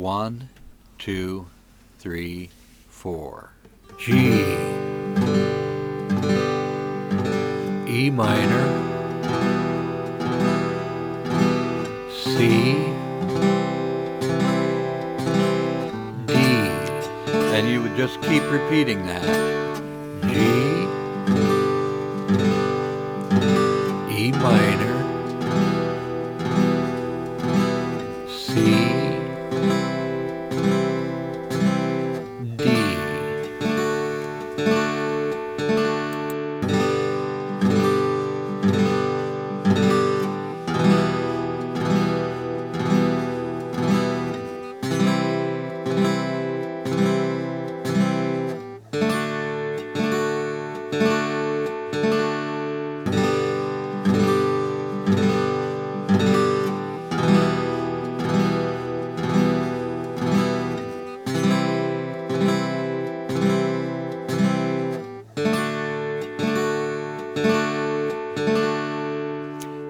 How-To-Ckange-Guitar-Chords-Backing-Track.wav